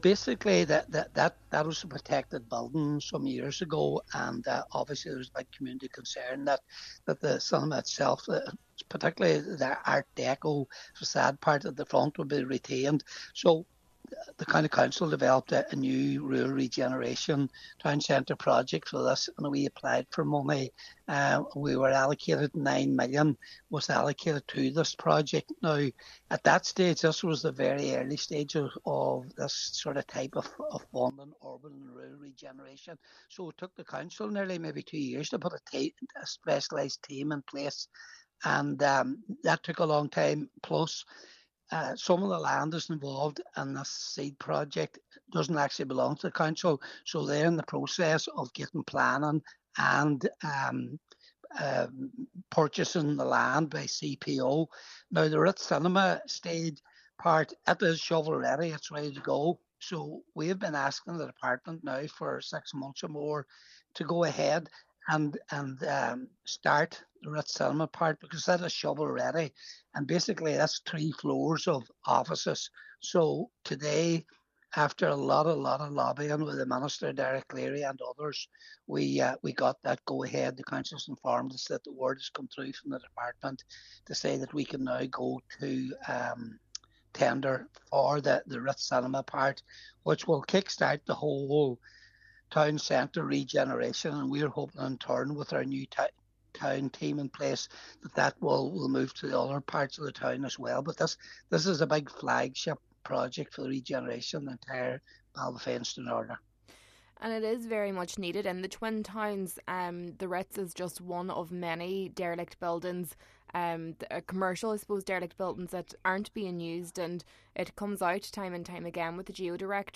Cllr Patrick McGowan says once complete, it will be a boost for the local economy: